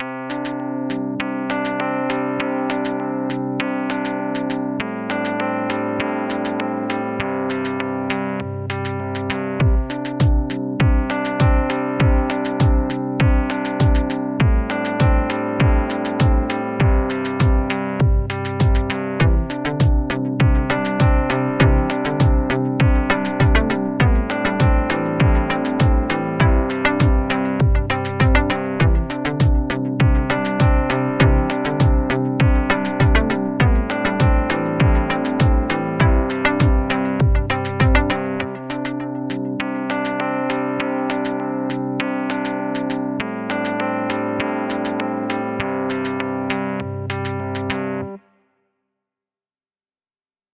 Category 🎵 Relaxation